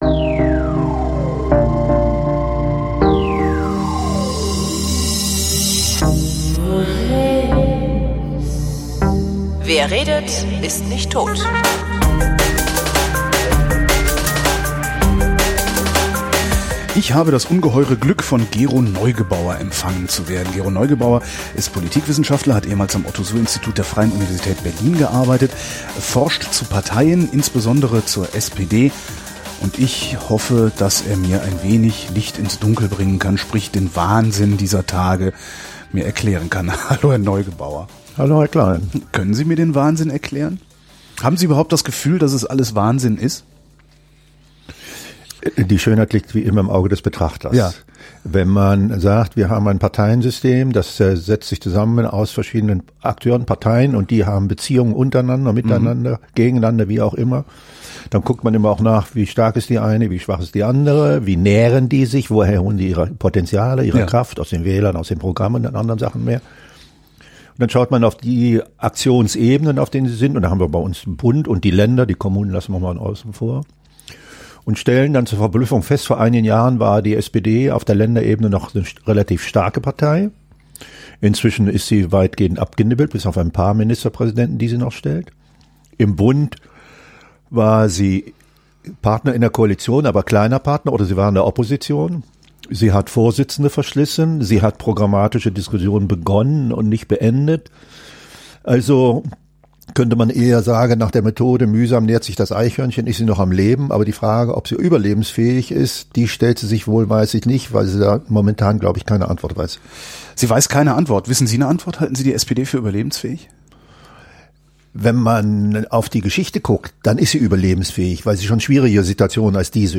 Ich hatte Gelegenheit, ihn zu treffen und mit ihm über den aktuellen Zustand der Partei und über mögliche Auswege aus deren Dilemma zu reden.